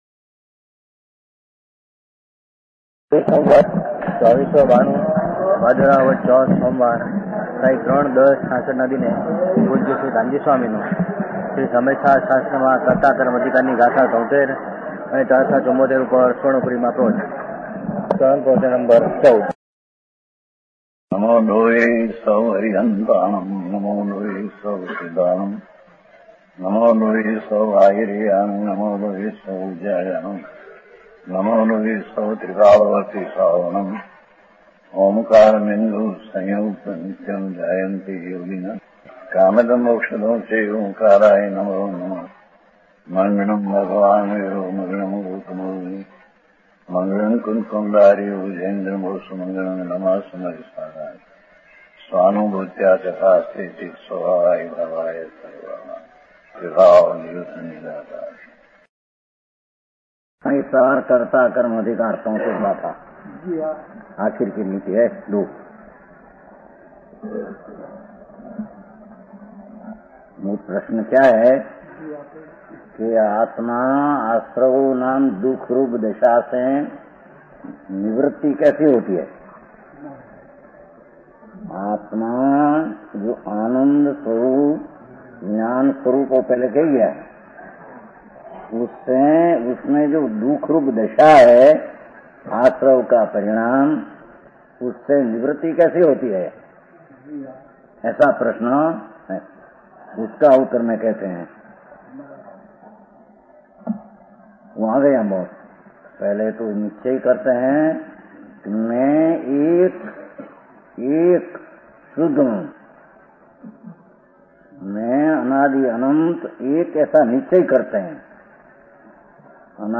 5th Pratishtha Day Celebration Pravachan | Parla Jin Mandir